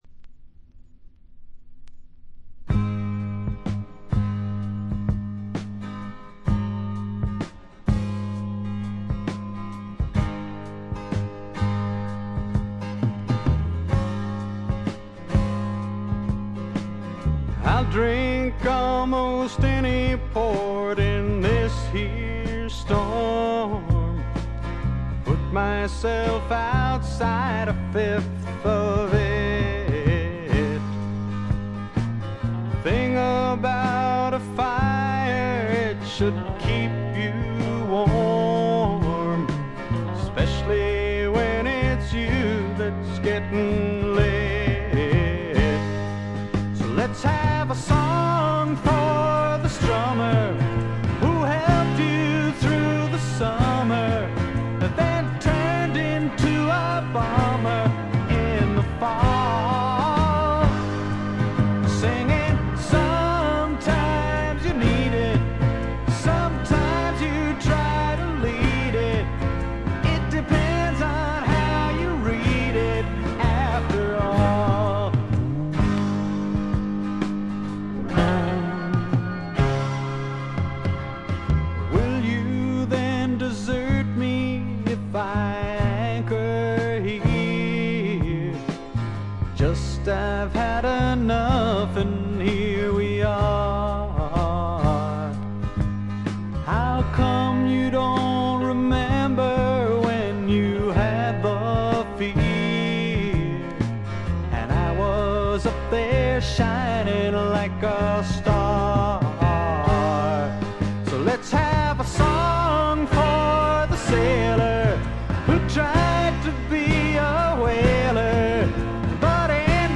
*** LP ： USA 1972
ほとんどノイズ感無し。
試聴曲は現品からの取り込み音源です。